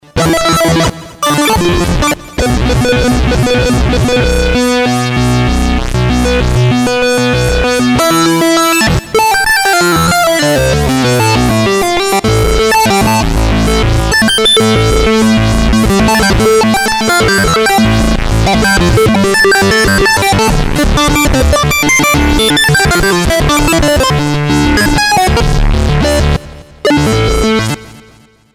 Polyphonic Synthesizer (2001)
Commodore 64 like